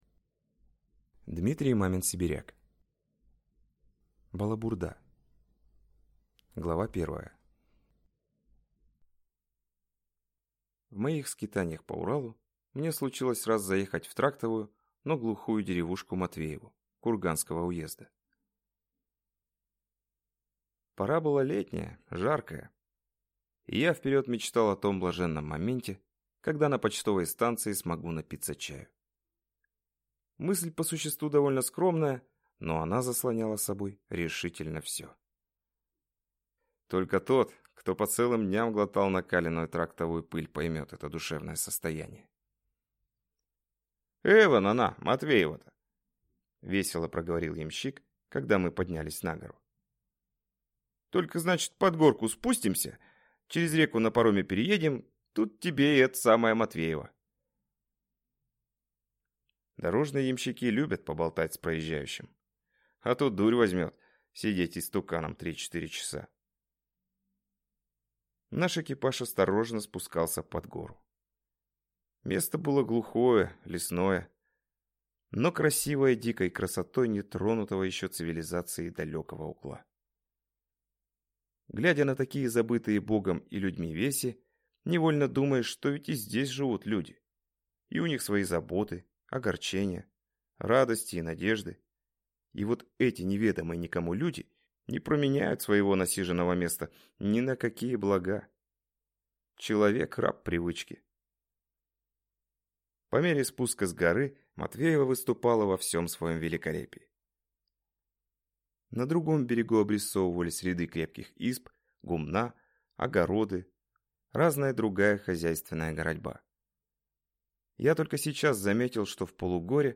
Аудиокнига Балабурда | Библиотека аудиокниг